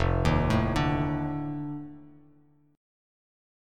F13 Chord
Listen to F13 strummed